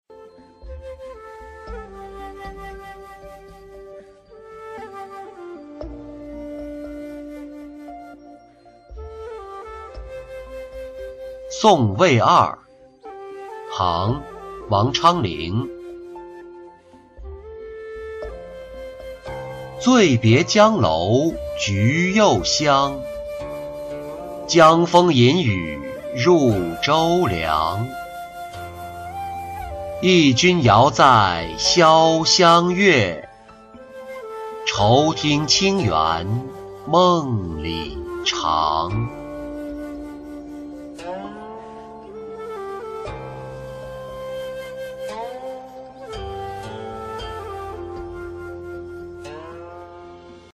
送魏二-音频朗读